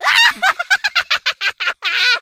colette_kill_vo_02.ogg